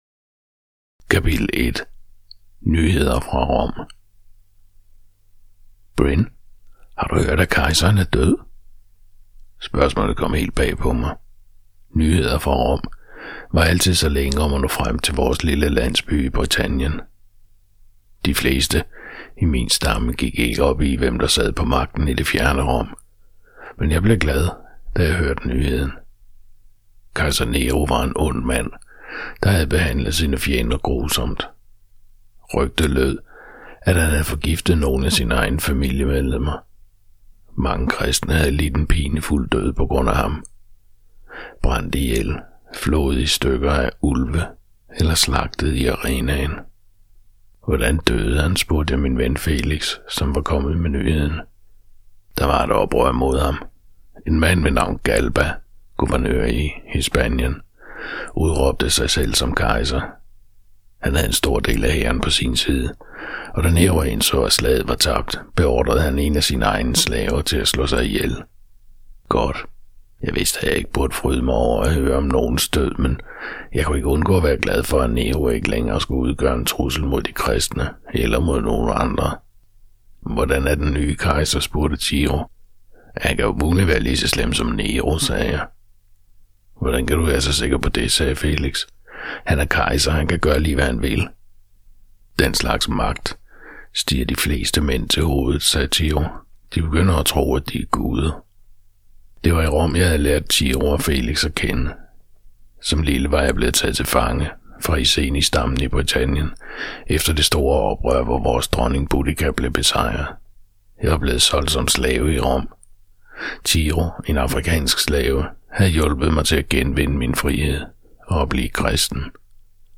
Hør et uddrag af Imperiet i krig Imperiet i krig Format MP3 Forfatter Kathy Lee Bog Lydbog 99,95 kr.